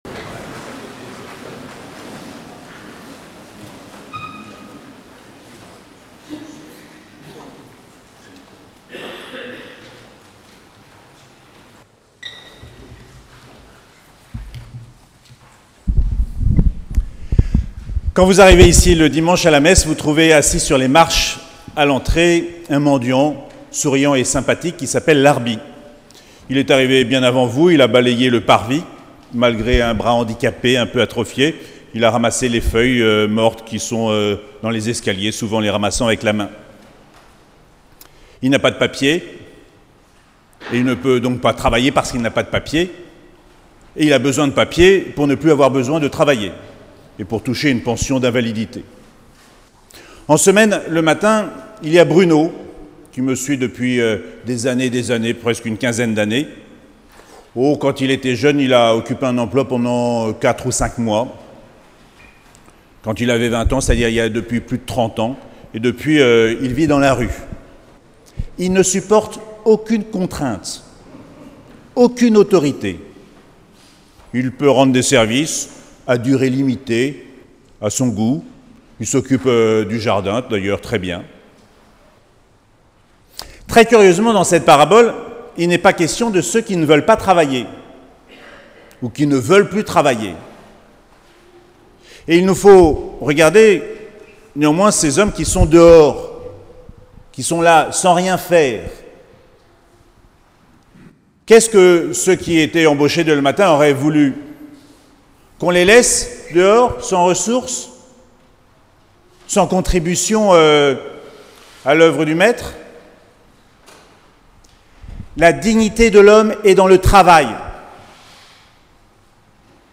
Toutes les homélies